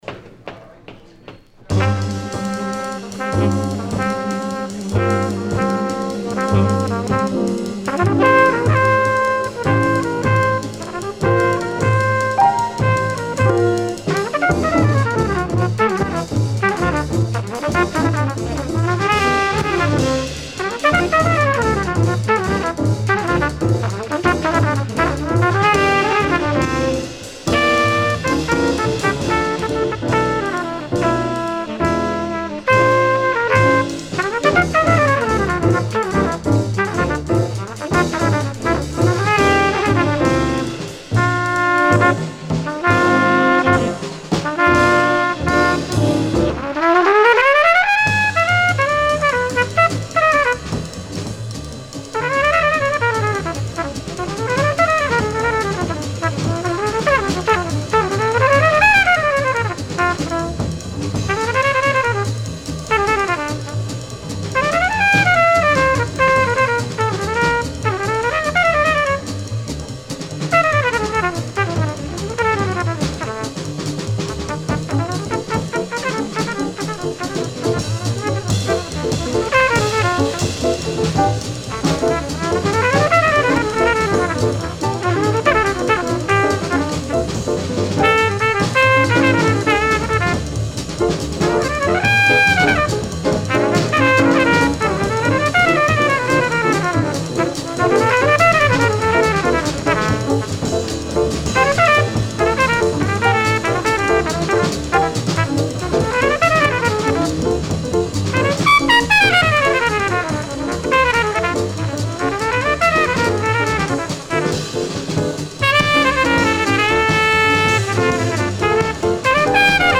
Recorded May 31, 1956 at Café Bohemia NYC